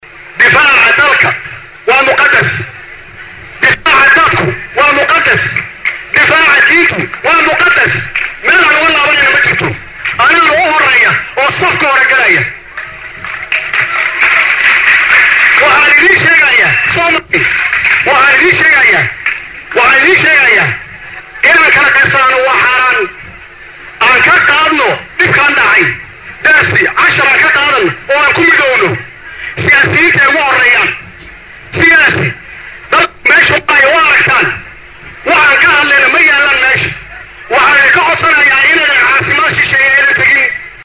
Hadalkan ayuu ka sheegay Madaxweynaha Soomaaliya kadib khudbad uu ka jeediyay Banaanbixii ugu balaarnaa oo maanta ka dhacay magalada Muqdisho, gaar ahaan Garoonka kubadda Cagta ee Banaadir Stadium.